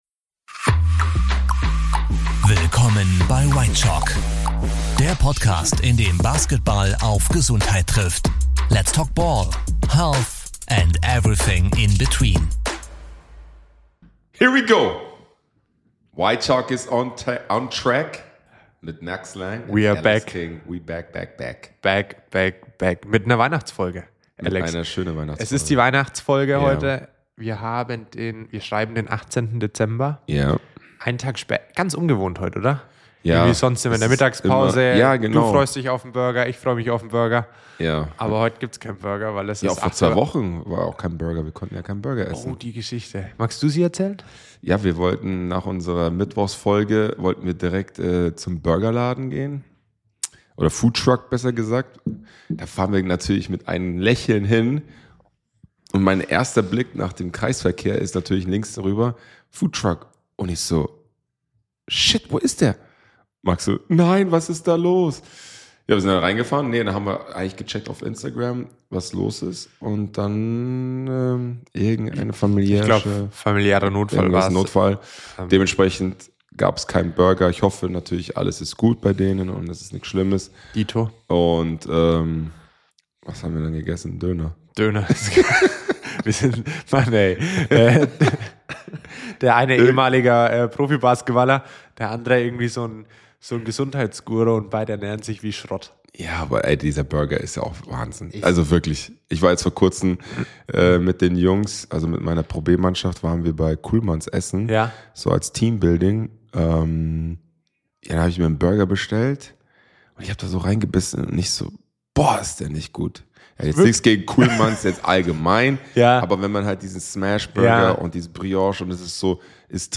• Warum Neujahrsvorsätze meistens nix bringen – außer Content • Spontanes Telefon-Interview aus dem Tourbus mit fiesen Entweder-Oder-Fragen • Und: Warum Döner manchmal besser ist als Coaching Neue Folge Whitechoc – jetzt überall hören, wo’s Podcasts gibt.